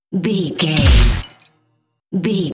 Dramatic hit hiss electricity debris
Sound Effects
heavy
intense
dark
aggressive
hits